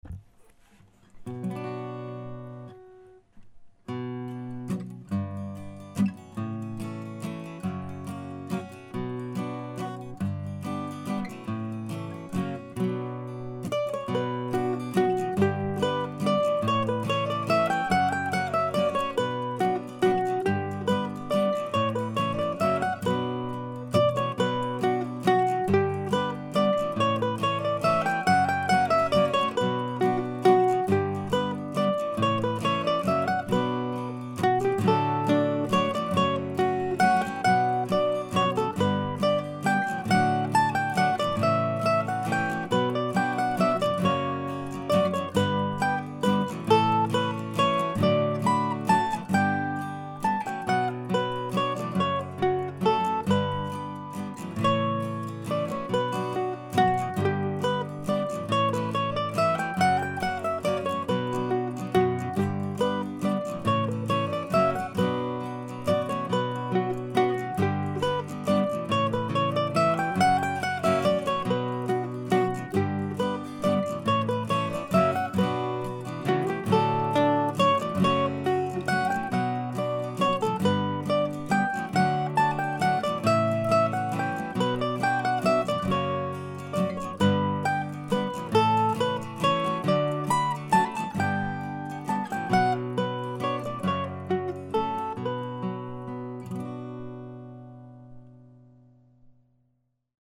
Old Books in Motion ( mp3 ) ( pdf ) Here's a recent waltz that reminds me of another tune I can't quite put my finger on.
I used a capo at the 2nd fret on the guitar.